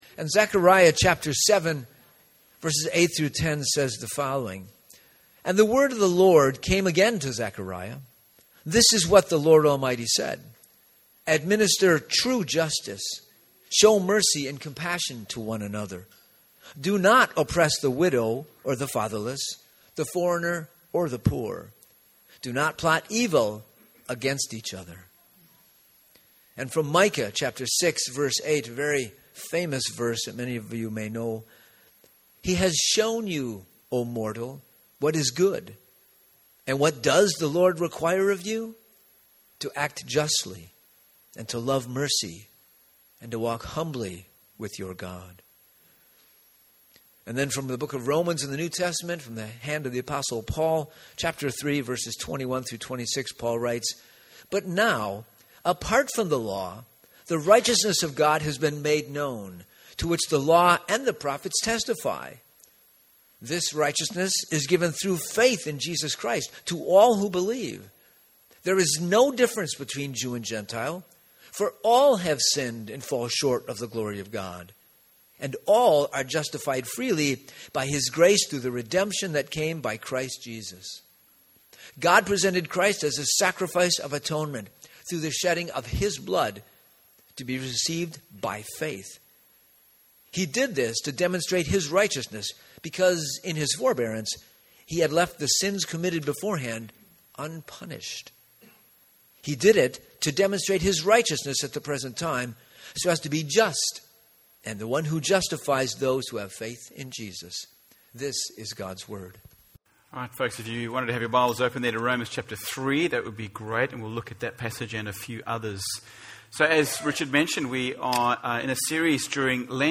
Sermon text: Zechariah 7:8-10, Micah 6:8 & Romans 3:21-26